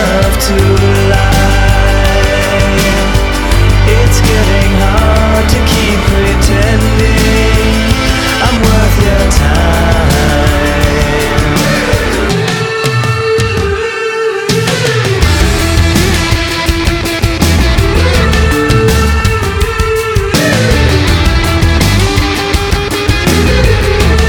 This is a sound sample from a commercial recording.
Includes prominent studio-added beats